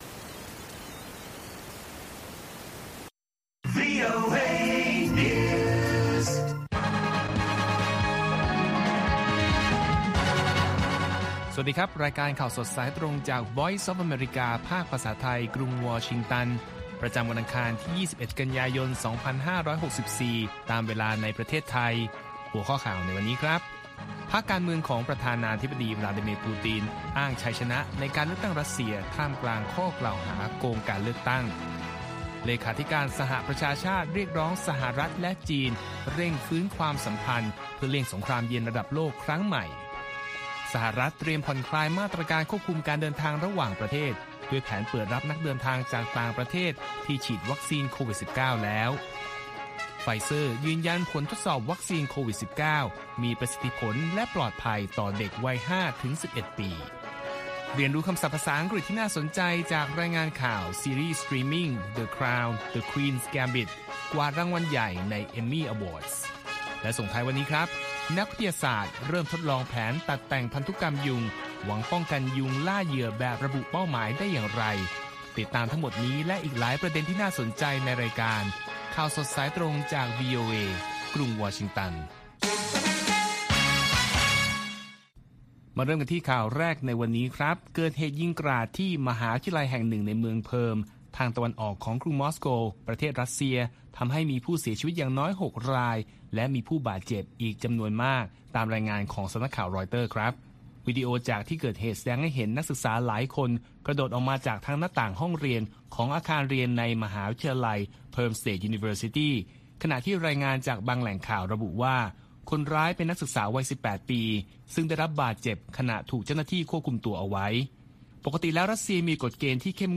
ข่าวสดสายตรงจากวีโอเอ ภาคภาษาไทย ประจำวันอังคารที่ 21 กันยายน 2564 ตามเวลาประเทศไทย